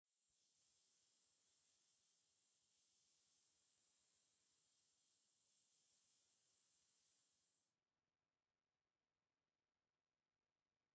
firefly_bush10.ogg